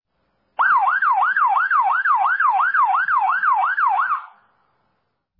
Police